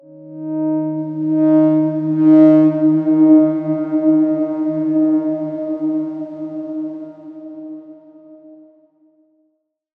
X_Darkswarm-D#3-mf.wav